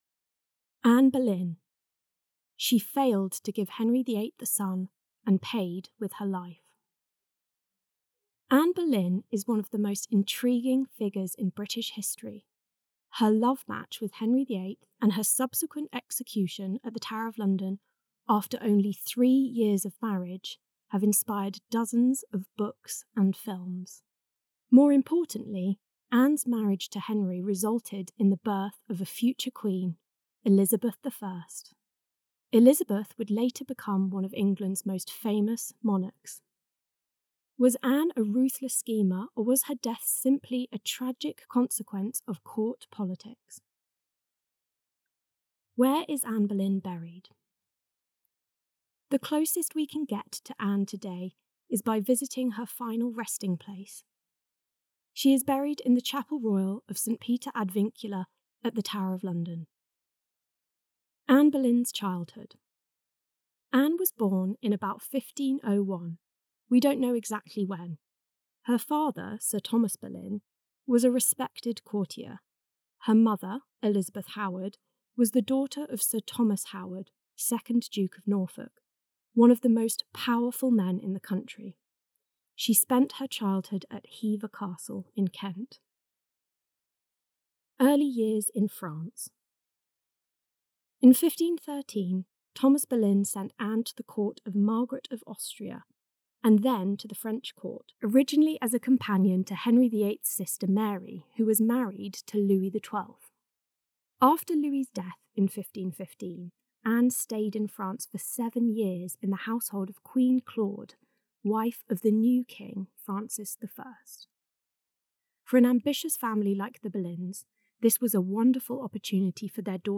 Listen to the contents of this page read by our team here, or keep scrolling to read about Anne Boleyn's life with images.